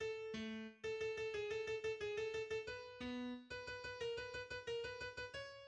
en la majeur
Genre Symphonie
Mesure 13 : entrée en imitation des violons et des violoncelles